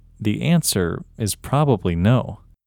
OUT – English Male 23